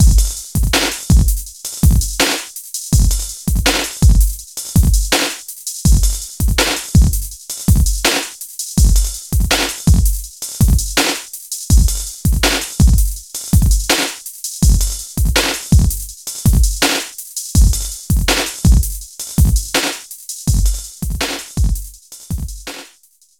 Octavox | Drums | Preset: Massive Detune
Elec-Drums-Massive-Detune.mp3